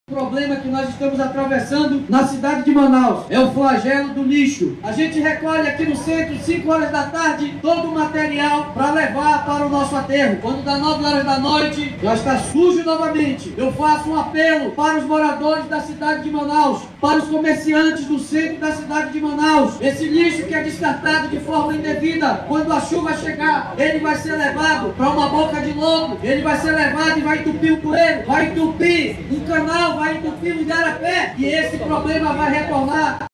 SONORA-1-DESCARTE-LIXO-.mp3